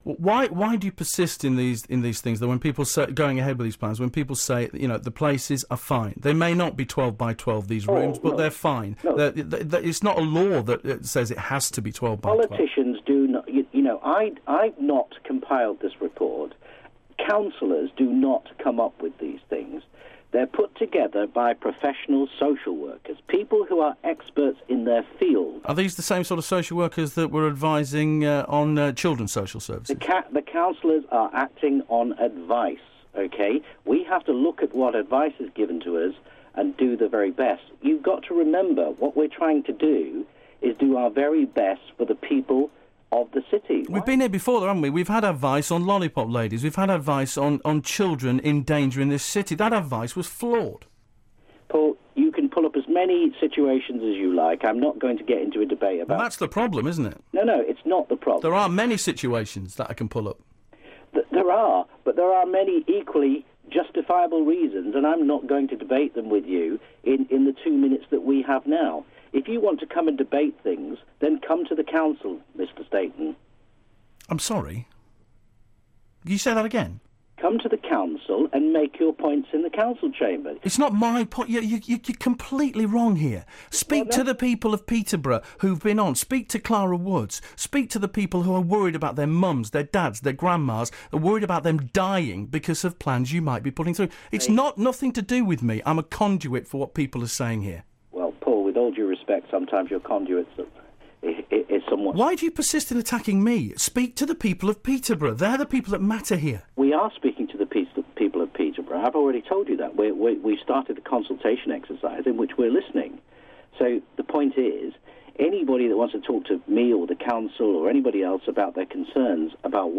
here's the second part of the interview.